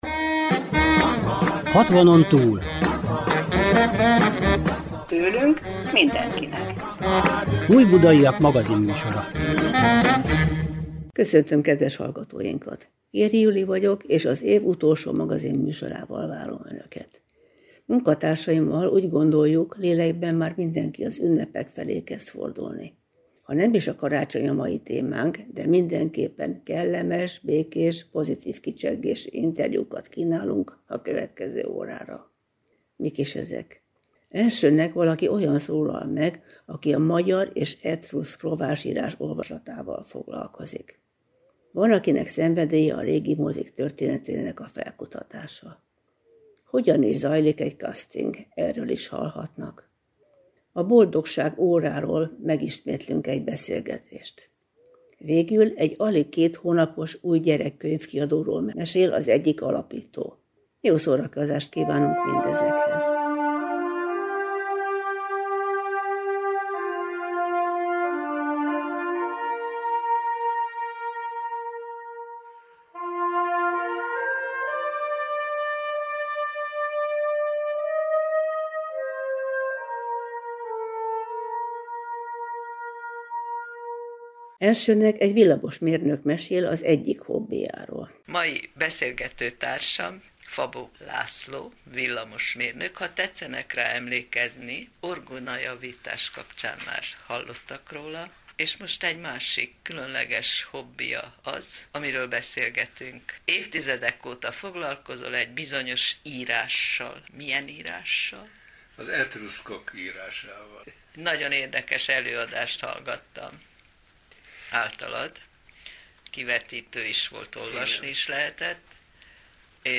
Magazinműsor a Civil Rádióban